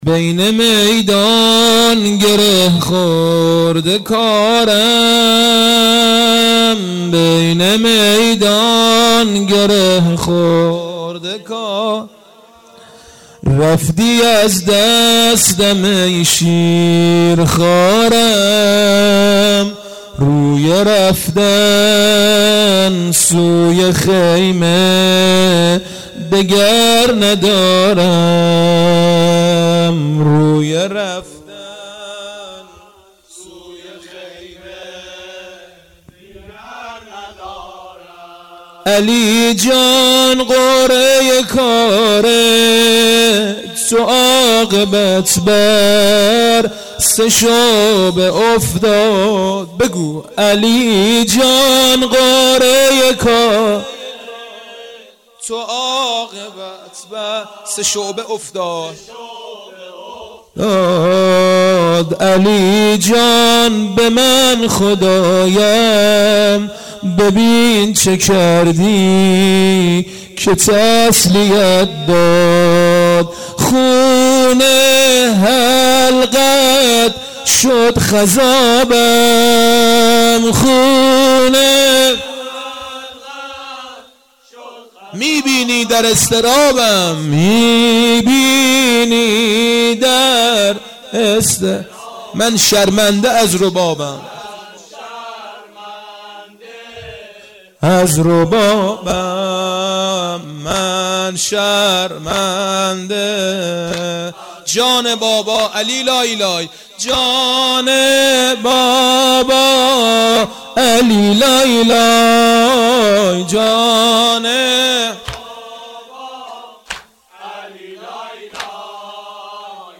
مداحی
محرم سال1397